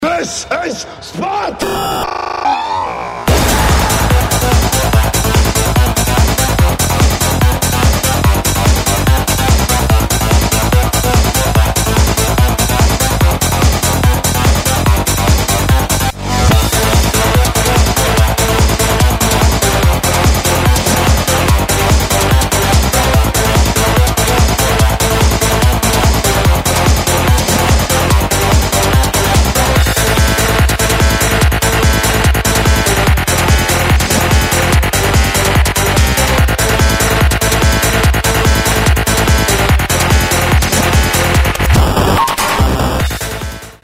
• Качество: 128, Stereo
громкие
электронная музыка
энергичные
быстрые
psy-trance
Hard Trance
psychedelic